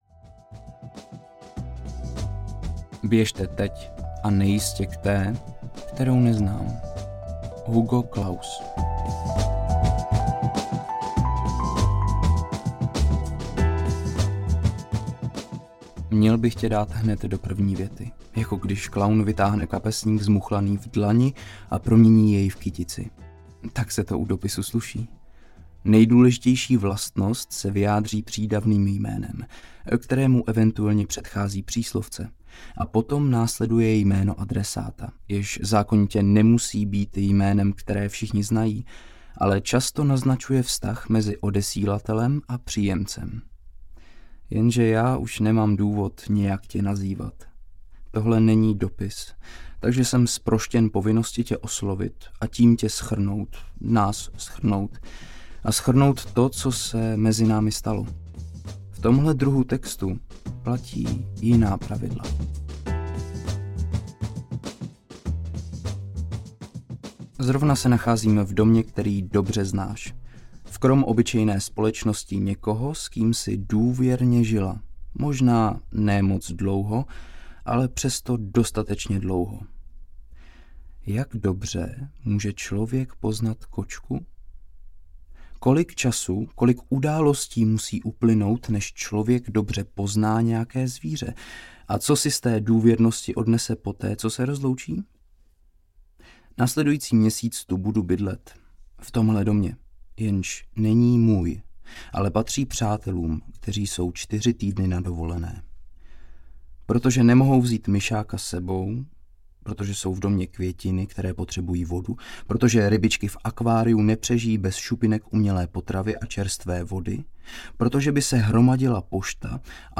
Abys věděla audiokniha
Ukázka z knihy